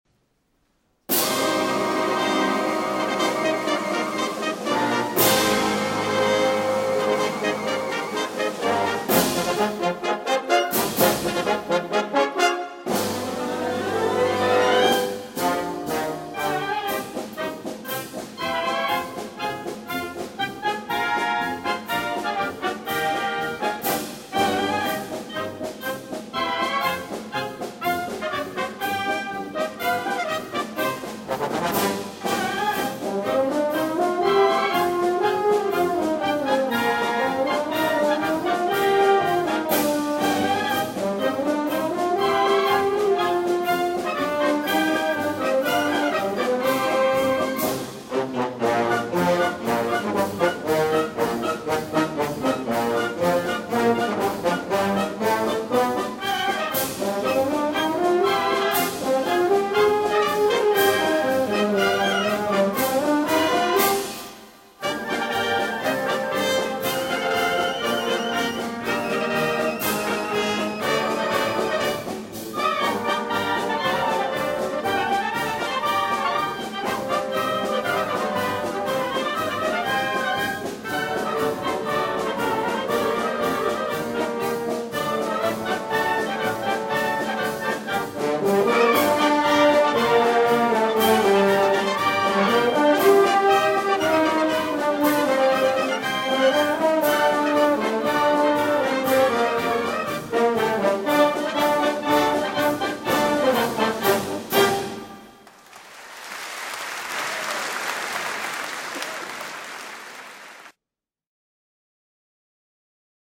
Click This Picture for IDF Music in Moscow Red Square - "Hevenu Shalom Aliehem" in Moscow Style